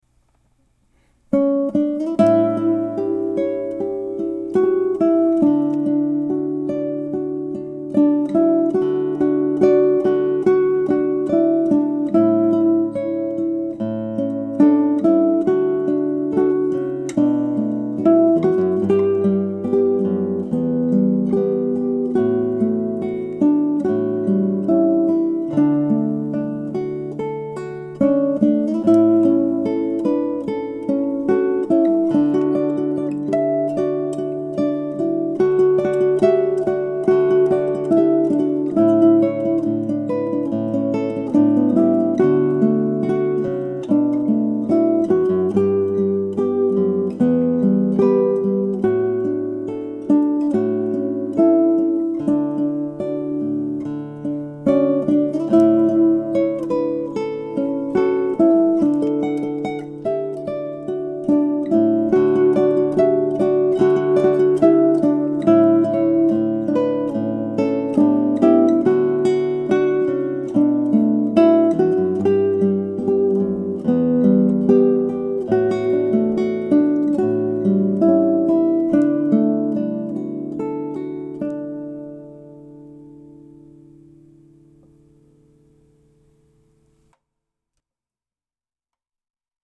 The slow ballad tempo should ease the strain of the changes a bit.
He Is Lord | Melody and chords